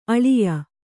♪ aḷiya